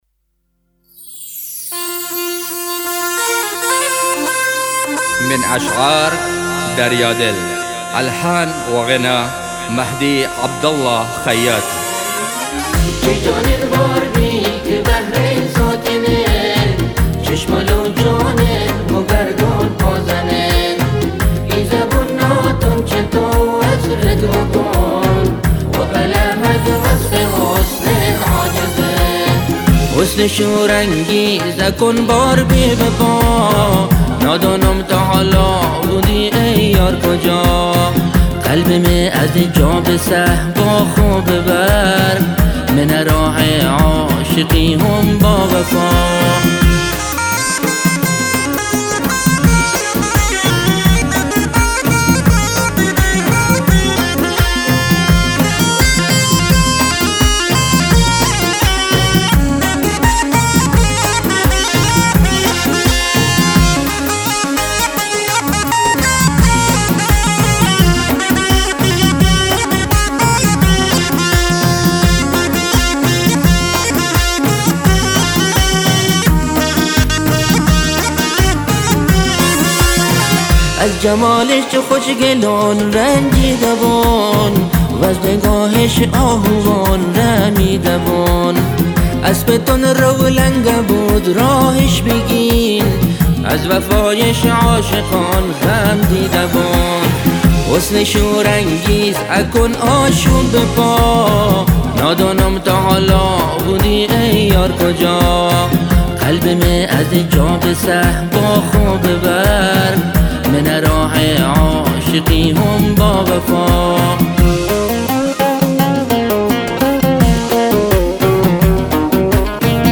جفتی
عود